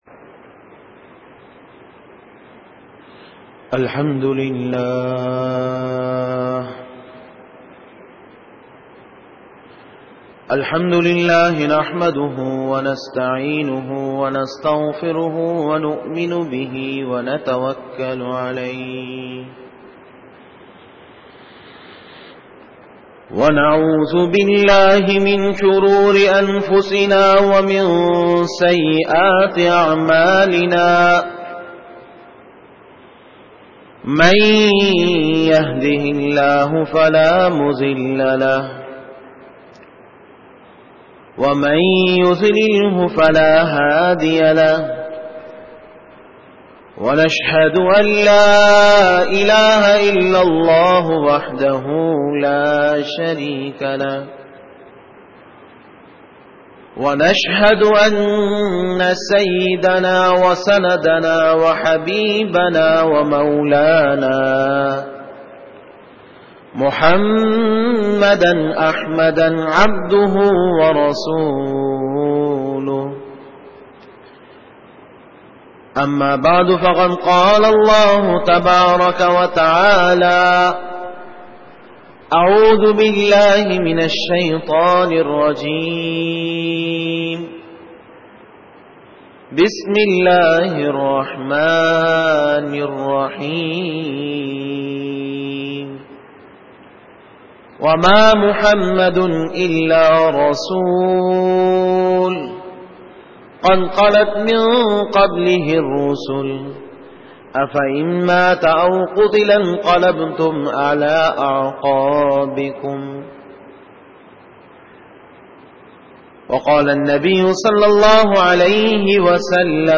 بیان جمعۃا لمبارک